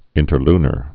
(ĭntər-lnər)